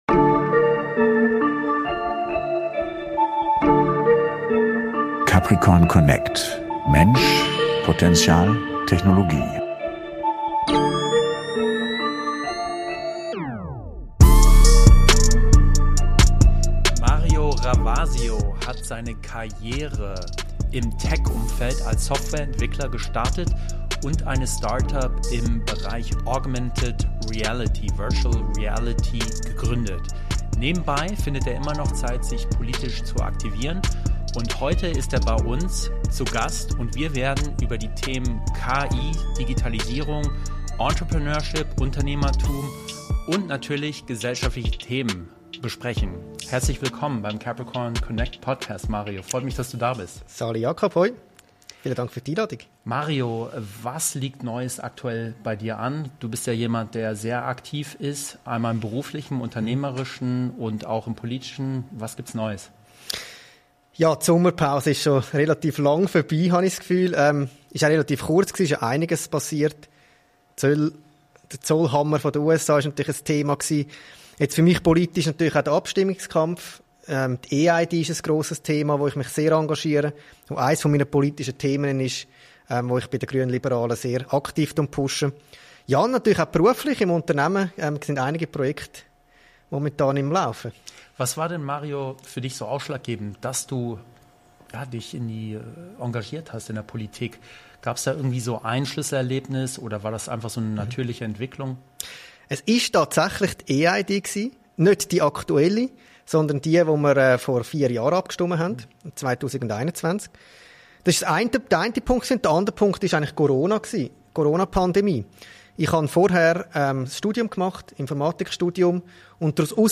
Ein Gespräch über Digitalisierung, Unternehmertum, Politik – und über das, was uns in Zukunft wirklich weiterbringt.